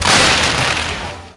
描述：2002年在英国的一次表演中，大口径烟花弹的发射和爆裂，用便携式模拟设备从不到50米的距离记录下来。
Tag: 场记录 焰火 贝壳